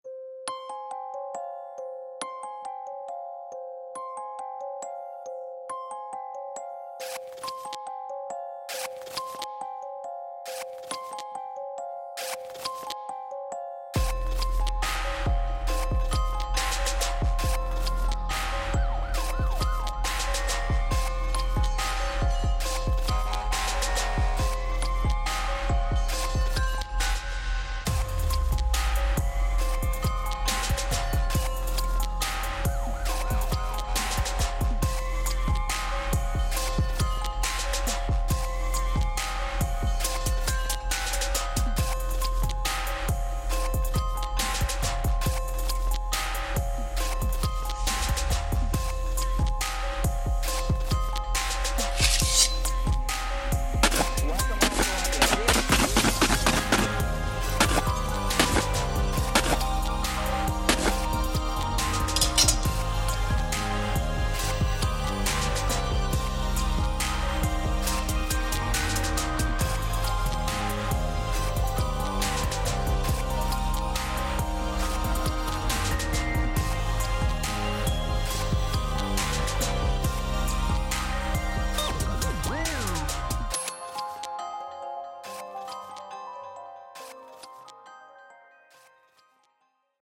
狂人は瞳に恋をする【2人声劇】